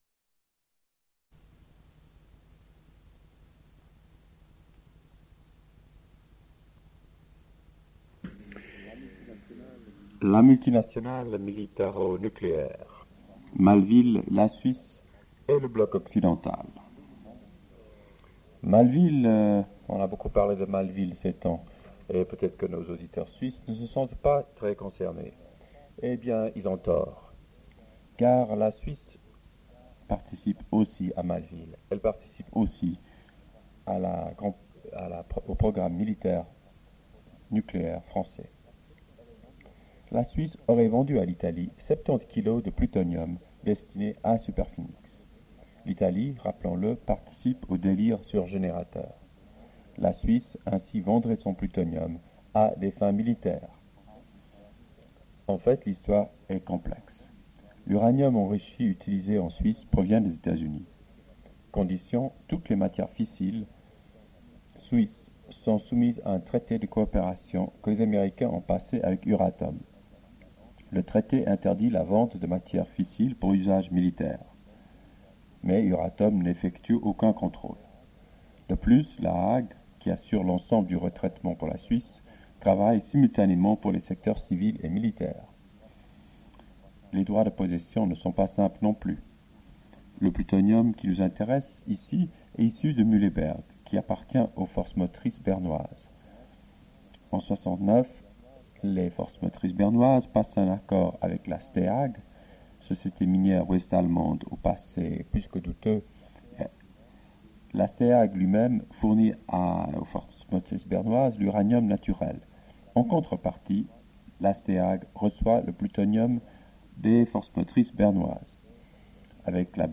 Difficile d'écoute car il retransmet en direct, un enregistrement qui s'entend en fond, ce qui génère pas mal de fautes de prononciation ainsi que la reprise de celles-ci afin de les corriger.